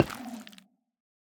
Minecraft Version Minecraft Version 1.21.5 Latest Release | Latest Snapshot 1.21.5 / assets / minecraft / sounds / block / sculk_catalyst / step4.ogg Compare With Compare With Latest Release | Latest Snapshot
step4.ogg